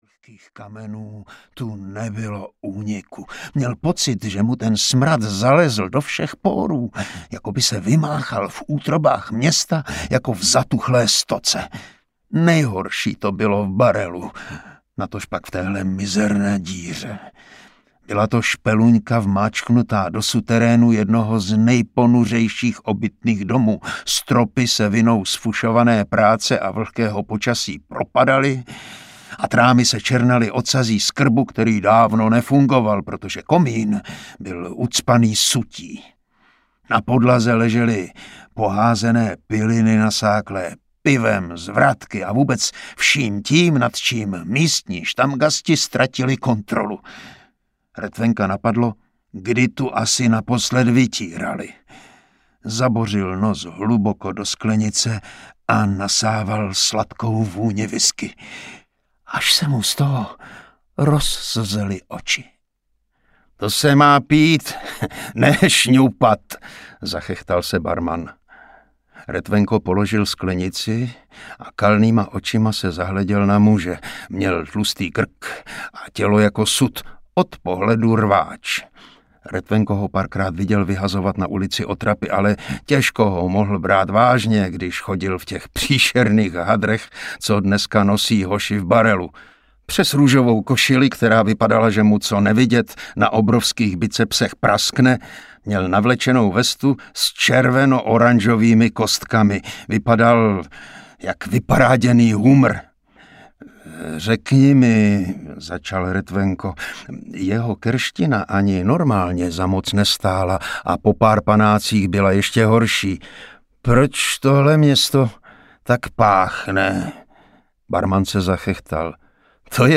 Prohnilé město audiokniha
Ukázka z knihy